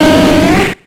Cri de Noctali dans Pokémon X et Y.